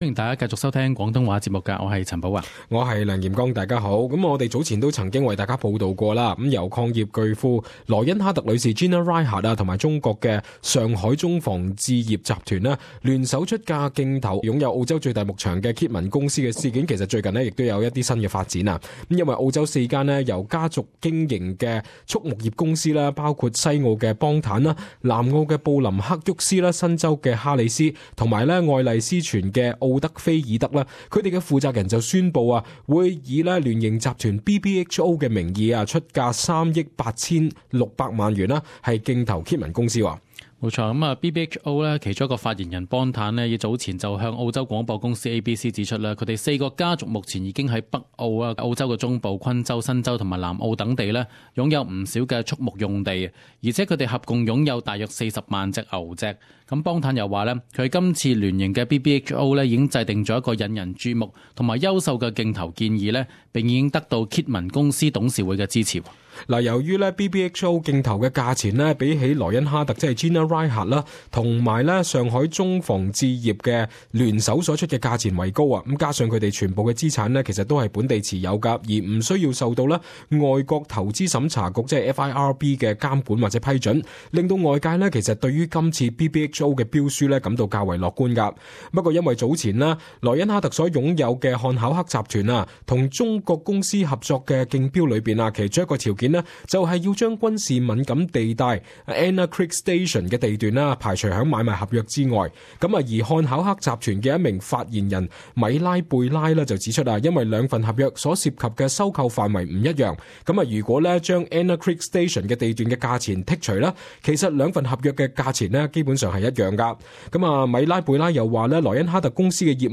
【时事报导】澳洲蓄牧业四大家族联手高价竞投Kidman公司